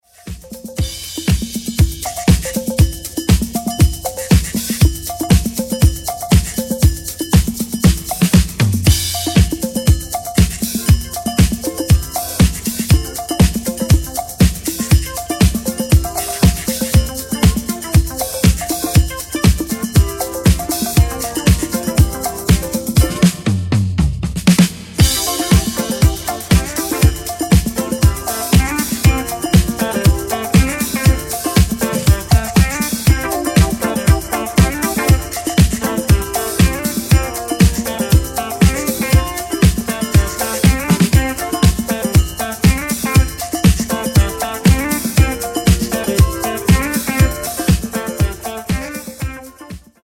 Funky, Afro, Soul Groove e JazzFunk Anni 70 e 80.